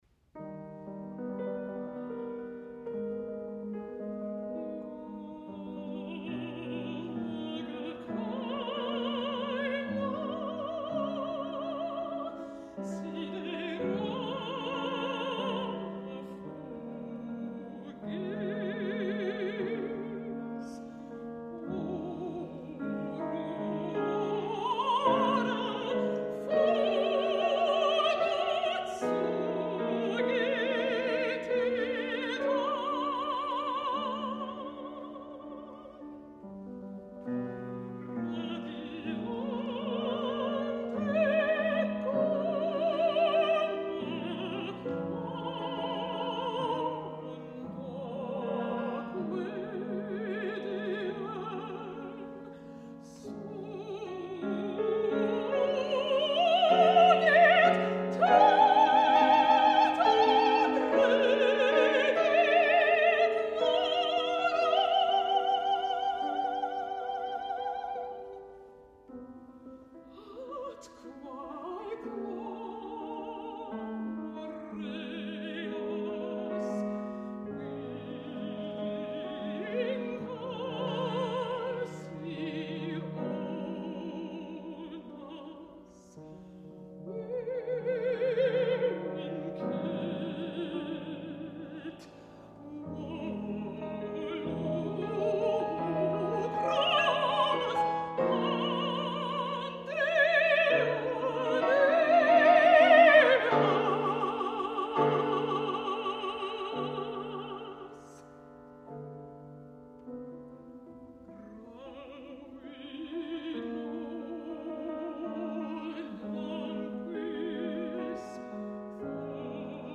Selections from this work-in-progress were performed with piano accompaniment at Willamette University, Salem, Oregon, on March 13, 2013.